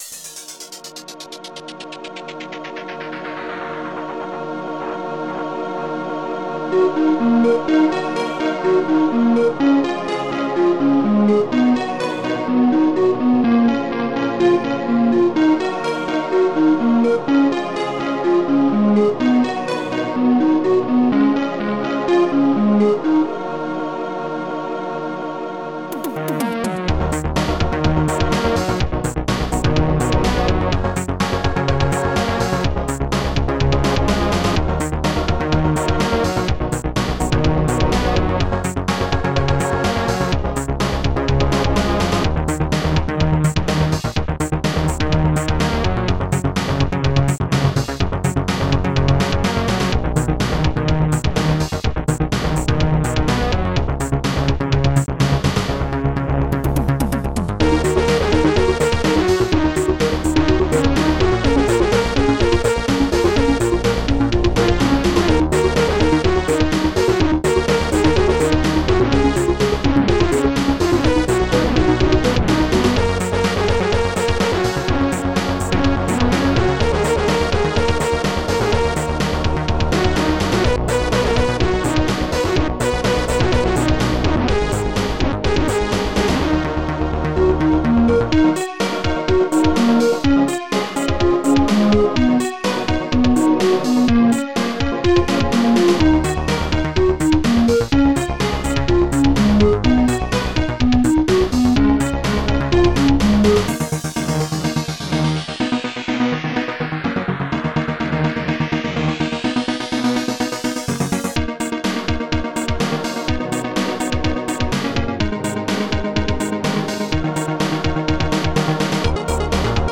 st-07:arcaneflute
st-09:hasnare
st-09:habassdrum
st-09:hahihat
ST-02:SoloBass
st-79:pitchsynth